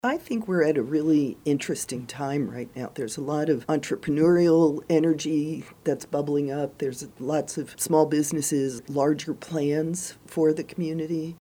Mayor Susan Adamchak says she’s excited that the position has drawn so much interest.